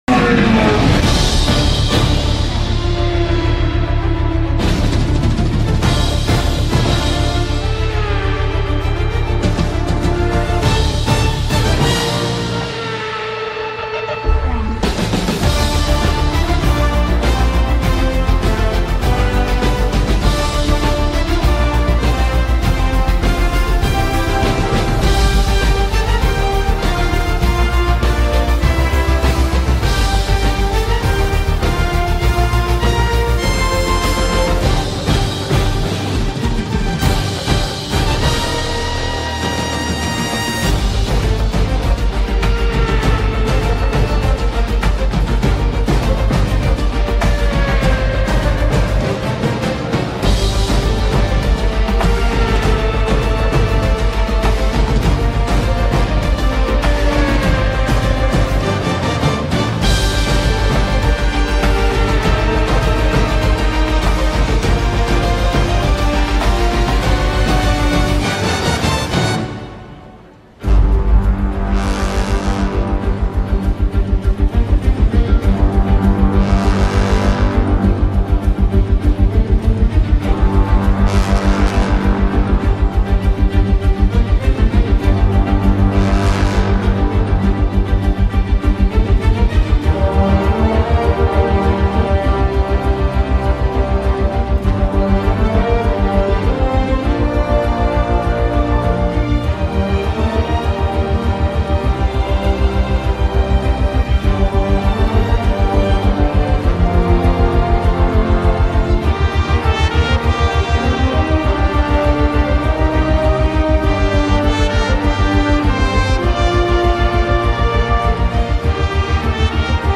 מקצבים שבניתי על קורג.